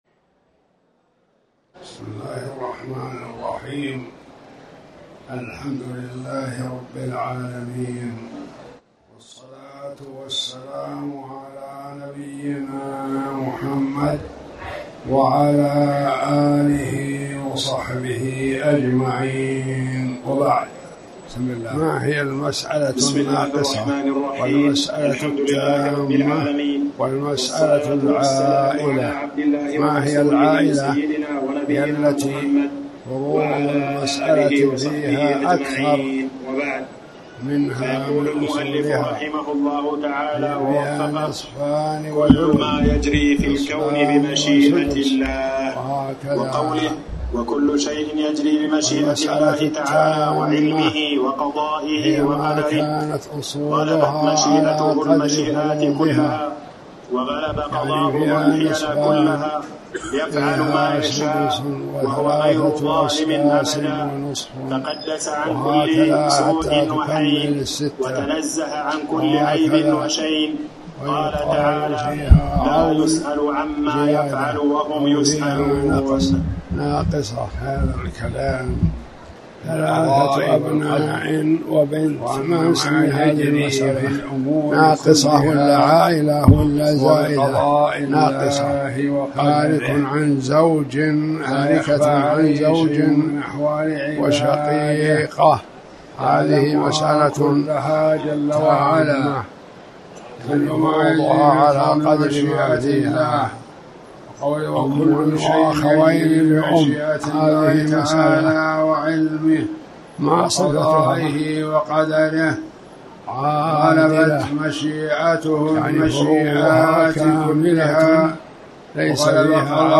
تاريخ النشر ٨ ربيع الثاني ١٤٣٩ هـ المكان: المسجد الحرام الشيخ